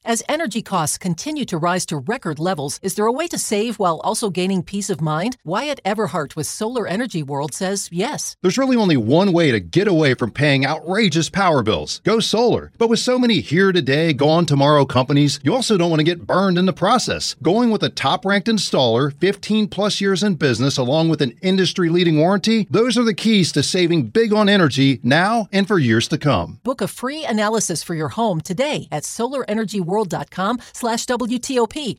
is answering some of the most pressing questions about shifting to solar power in 2025 in the new 5-part WTOP interview series below.